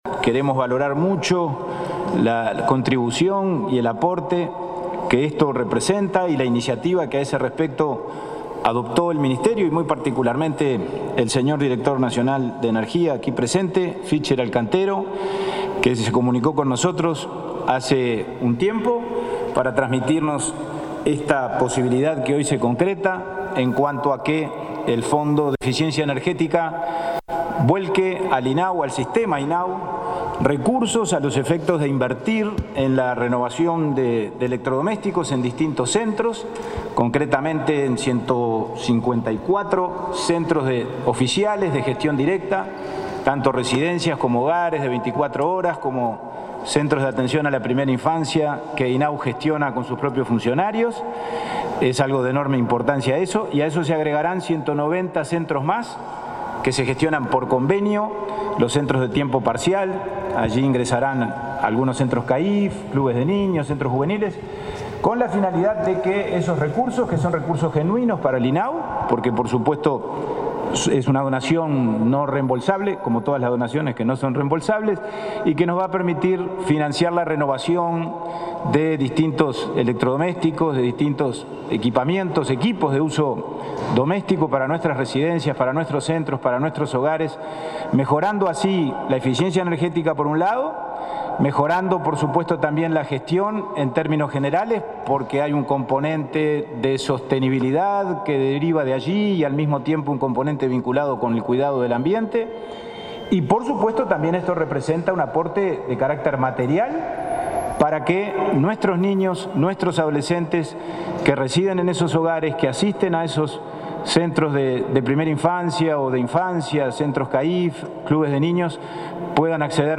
Palabras de autoridades en convenio INAU-DNE
El presidente del Instituto del Niño y el Adolescente del Uruguay (INAU), Pablo Abdala, y el titular de la Dirección Nacional de Energía (DNE),